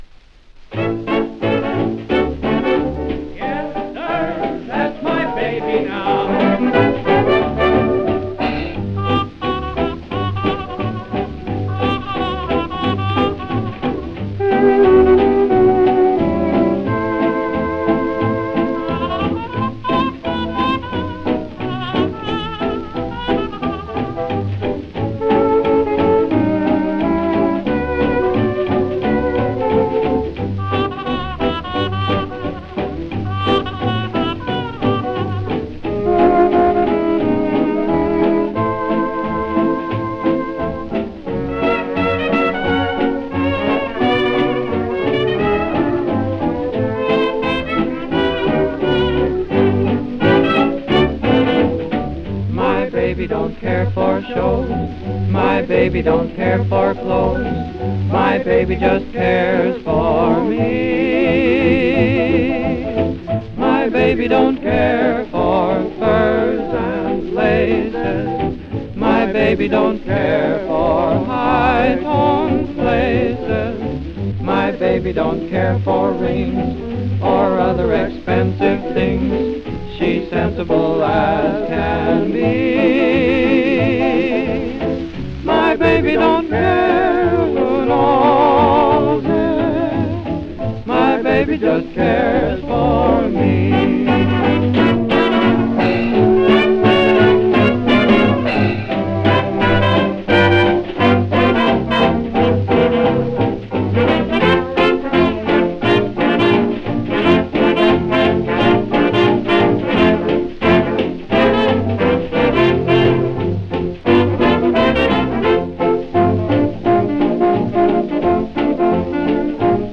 Fox Trot
Vocal - Refrain
Формат:Пластинки, LP, Compilation, Mono
Жанр:Jazz, Latin